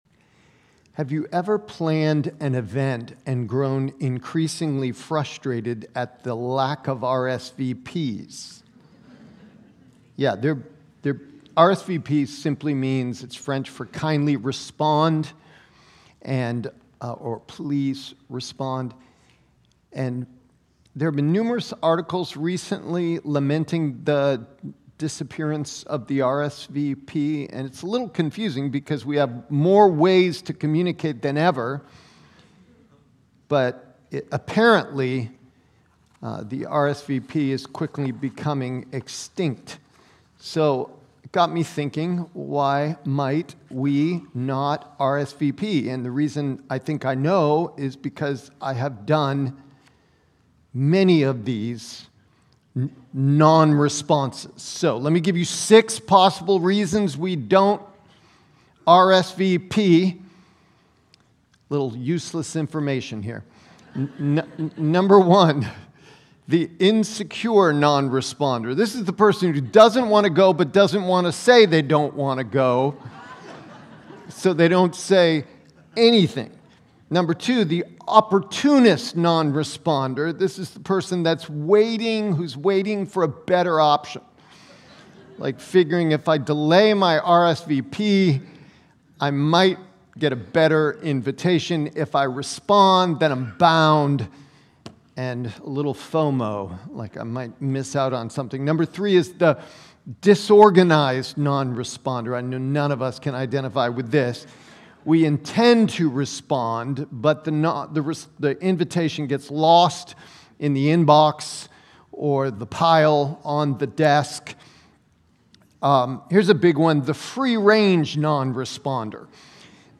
Receiving First Orders – 1/12/25 Sermons Archive - North Hills Church podcast